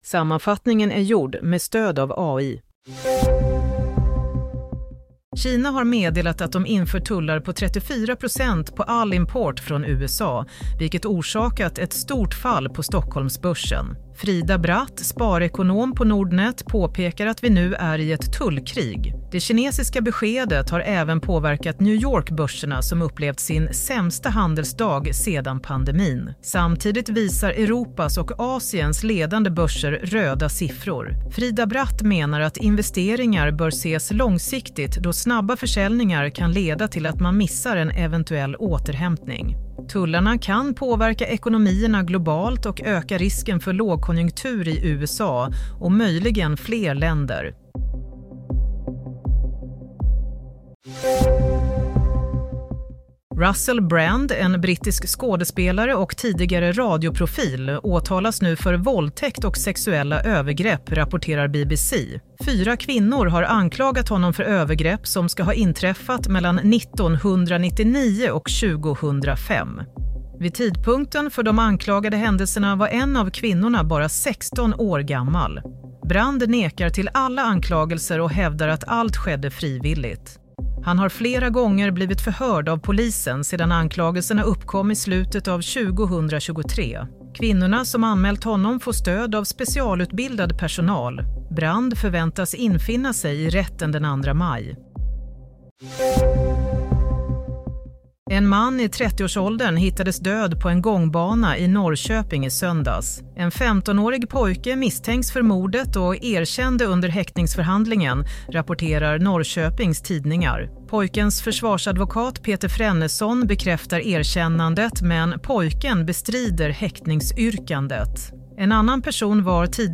Sammanfattningen av följande nyheter är gjord med stöd av AI.